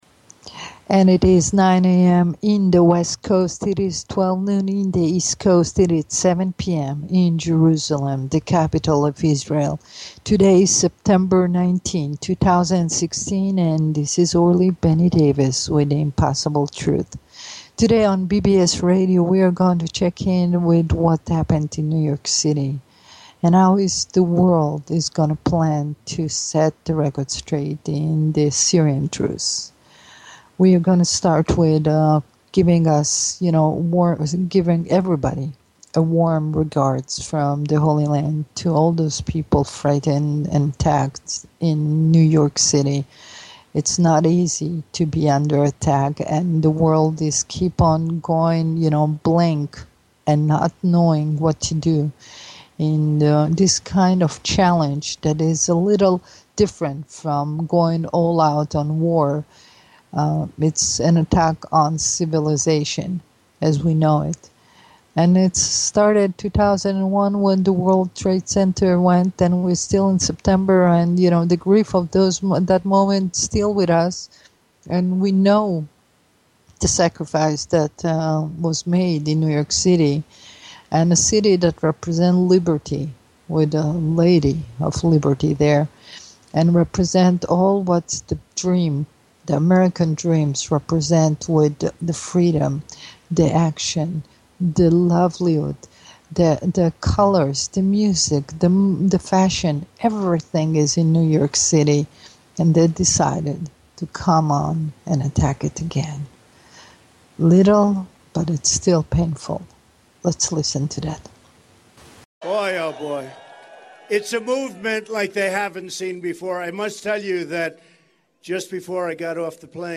The Impossible Truth on BBS Radio.